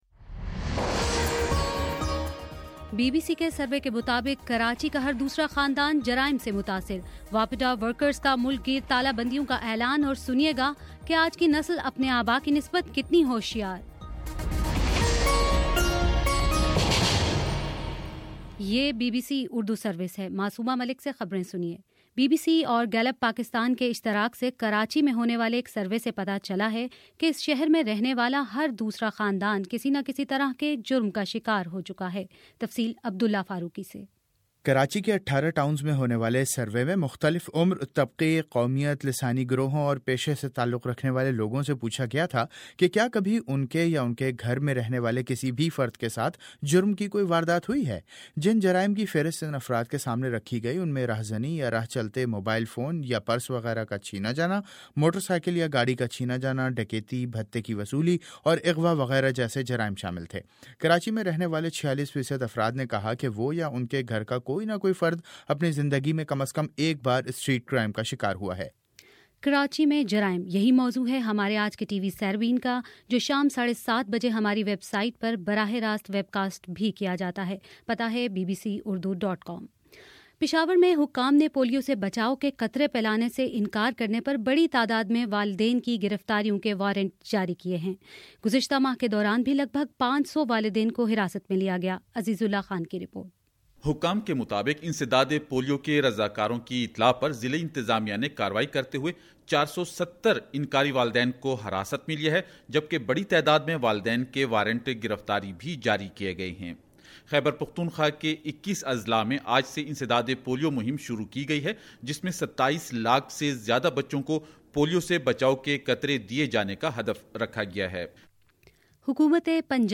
مارچ 02: شام سات بجے کا نیوز بُلیٹن